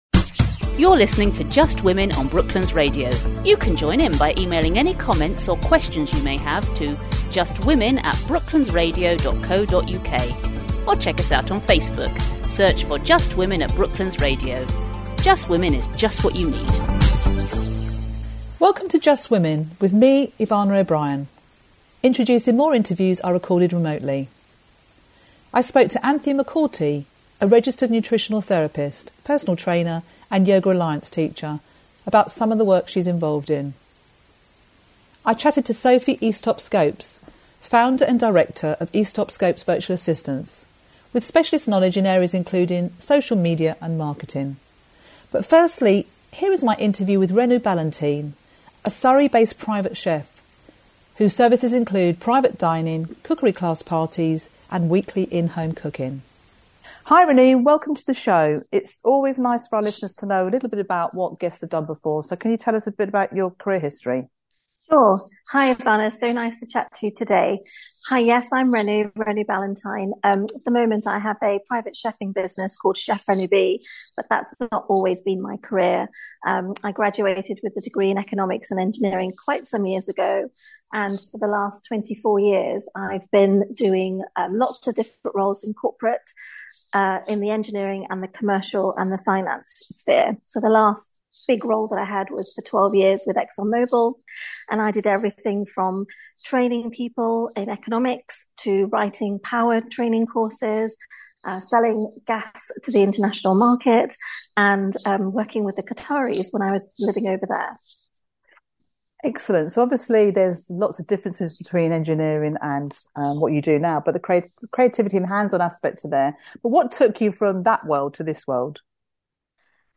Brooklands Radio: Interviewed for the Just Women segment (March 2023)